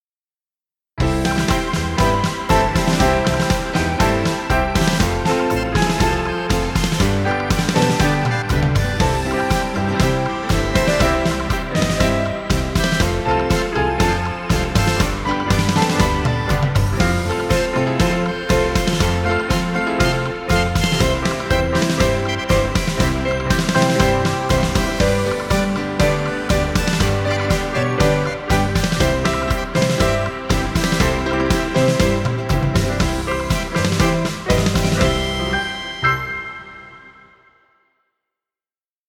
Tango music for video.